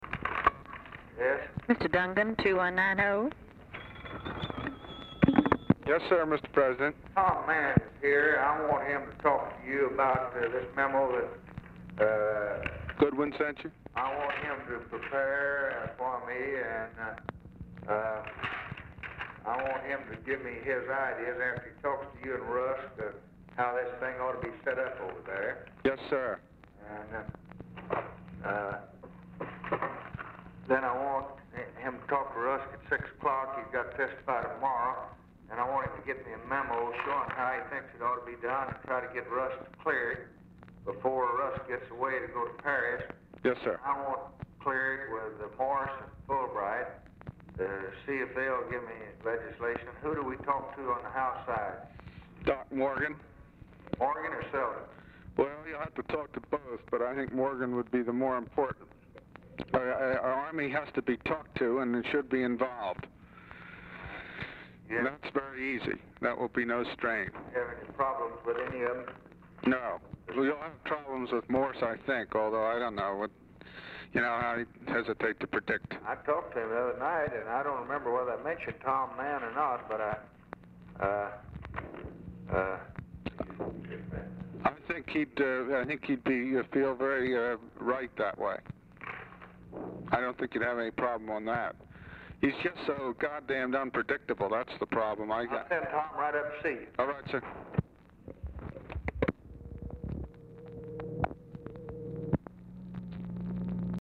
Telephone conversation # 413, sound recording, LBJ and RALPH DUNGAN, 12/11/1963, 3:36PM | Discover LBJ
Format Dictation belt
Location Of Speaker 1 Oval Office or unknown location
Specific Item Type Telephone conversation